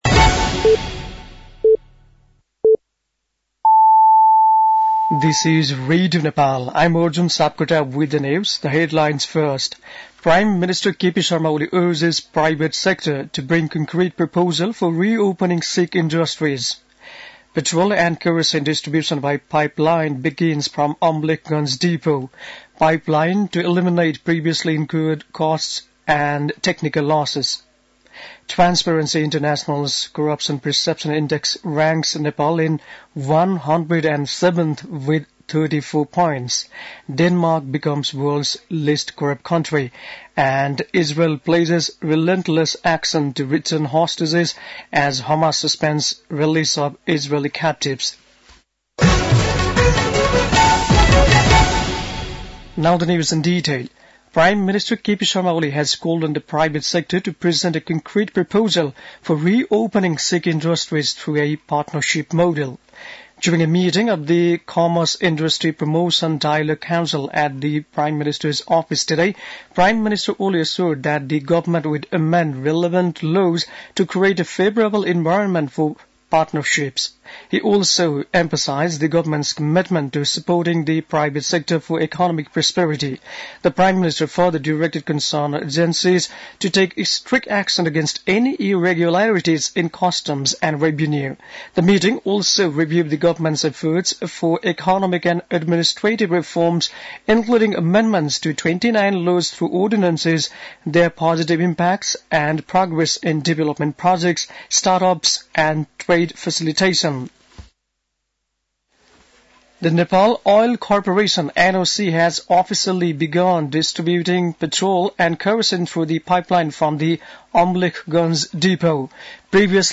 बेलुकी ८ बजेको अङ्ग्रेजी समाचार : ३० माघ , २०८१
8-pm-english-news-10-29.mp3